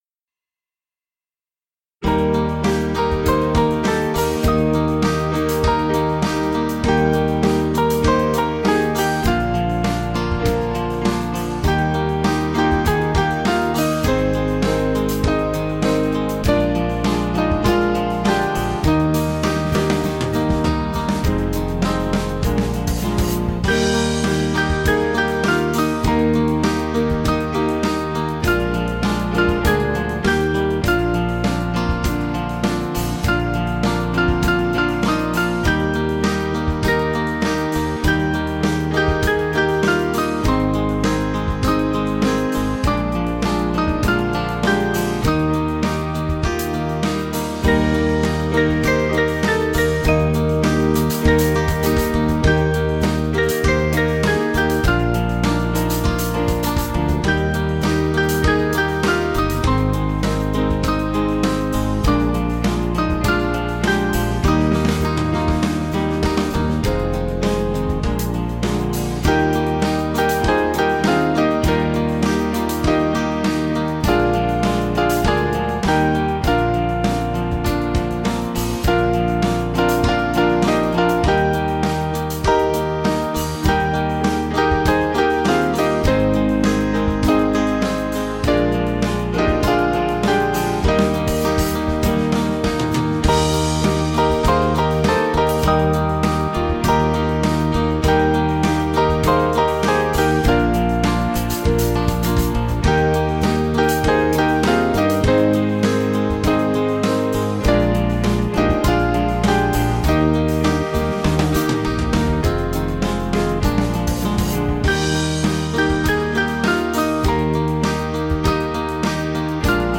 Small Band
(CM)   4/Eb 494.8kb